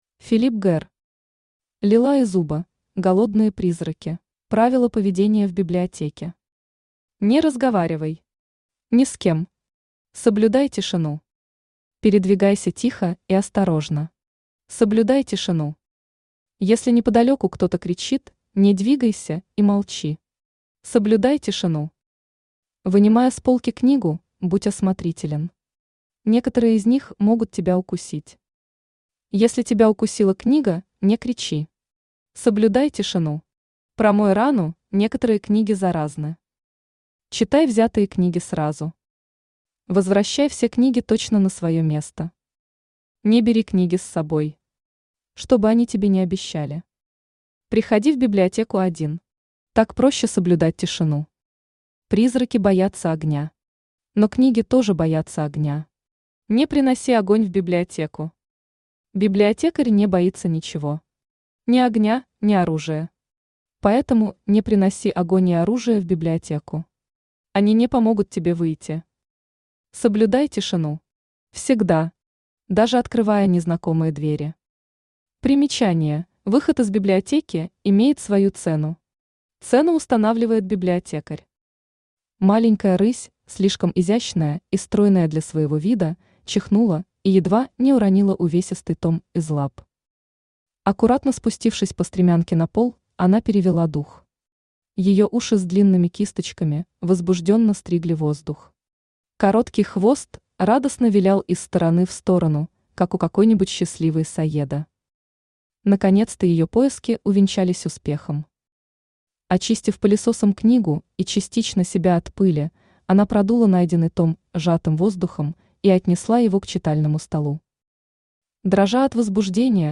Аудиокнига Лила Изуба: Голодные призраки | Библиотека аудиокниг
Aудиокнига Лила Изуба: Голодные призраки Автор Филип Гэр Читает аудиокнигу Авточтец ЛитРес.